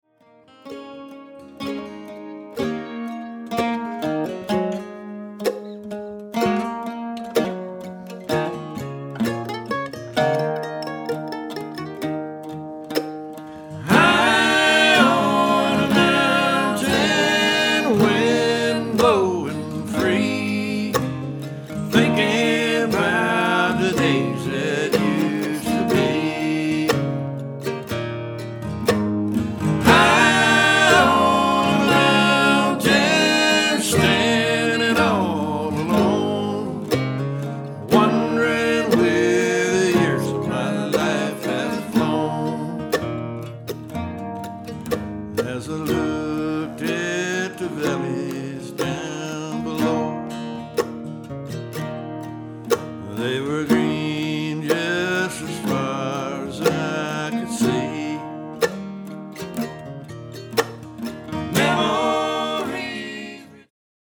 Traditional Acoustic Music